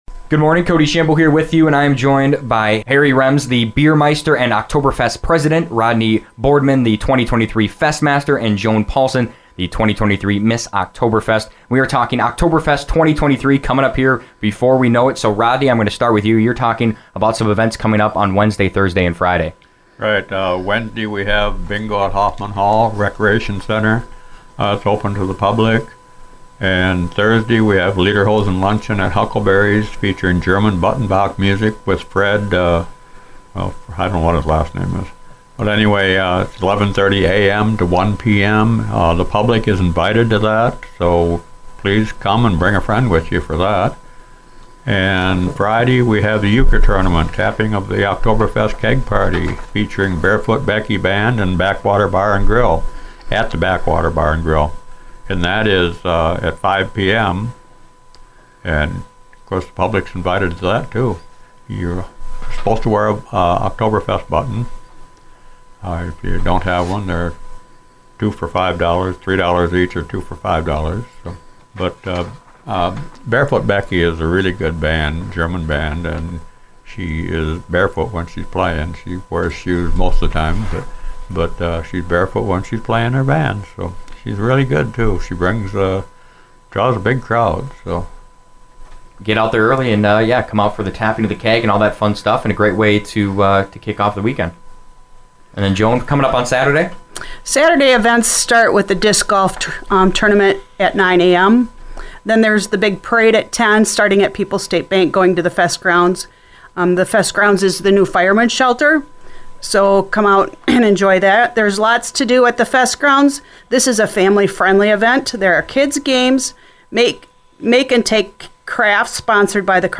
Interviews
oktoberfest-interview.mp3